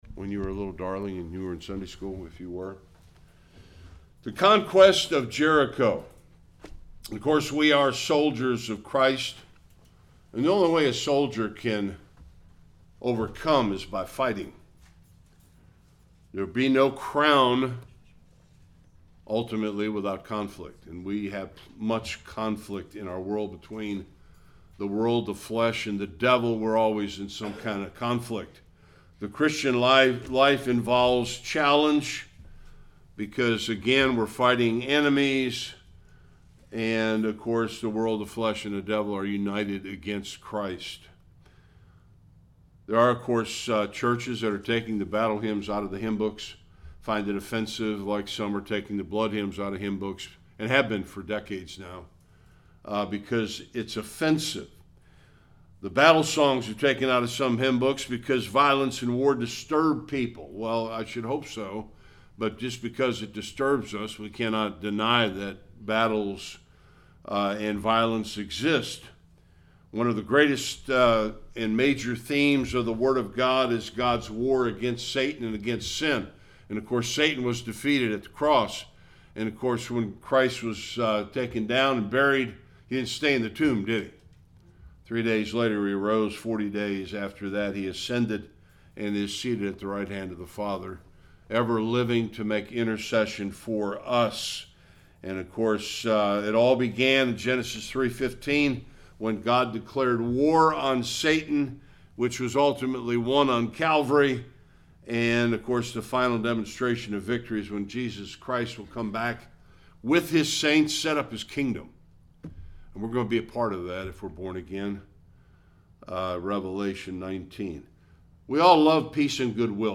1-27 Service Type: Sunday School The first great victory in the promised land